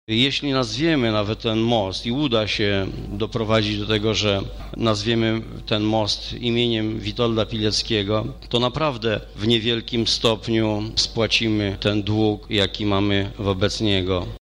O szczegółach mówi Stanisław Brzozowski- radny miasta Lublin